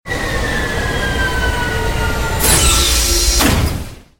shipland.ogg